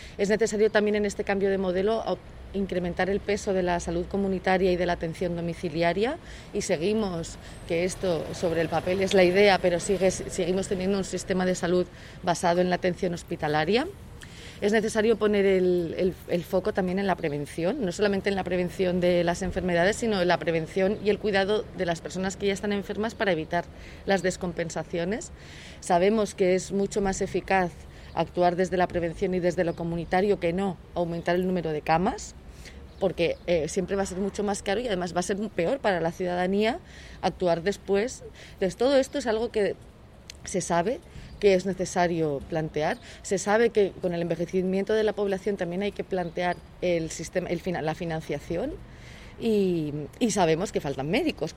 Les declaracions de la diputada, acompanyada dels membres del grup municipal de la formació taronja a la ciutat, es van realitzar davant l’ambulatori de Les Fontetes perquè, indica Navarro, que estigui tancat i que les urgències s’hagin de fer al CUAP constaten el trasllat que ha de fer la ciutadania d’un barri tant poblat com el de Les Fontetes per rebre atenció mèdica.
Declaracions de Blanca Navarro: